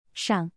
This tone starts high and then falls sharply, as demonstrated in the examples here: